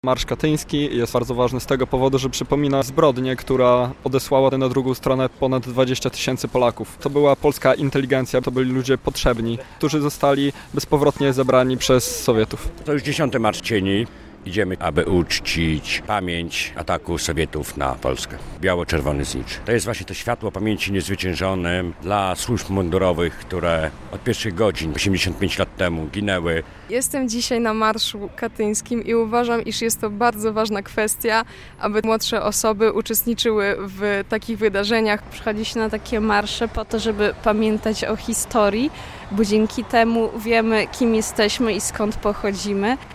X Podlaski Marsz Cieni w Białymstoku - relacja